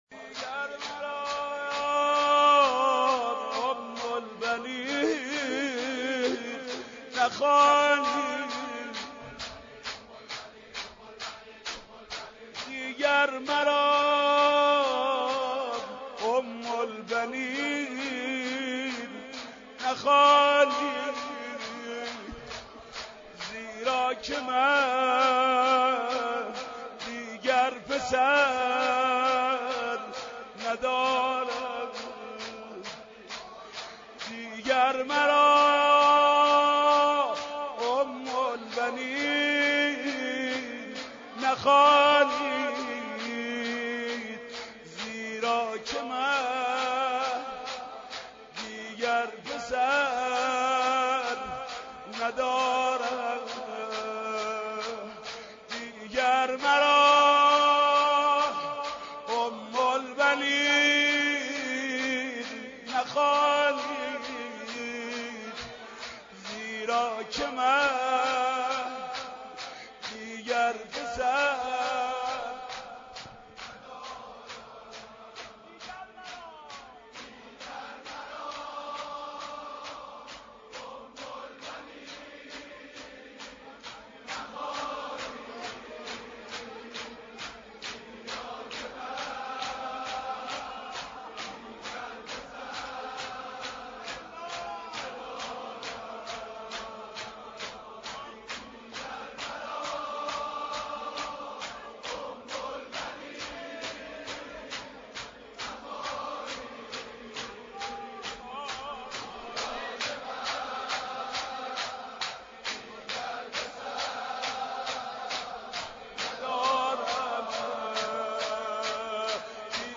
دانلود مداحی وفات حضرت ام البنین (ع) – محمود کریمی – مجله نودیها
مداحی و روضه حاج محمود کریمی وفات حضرت ام البنین (ع)